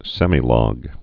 (sĕmē-lôg, -lŏg, sĕmī-)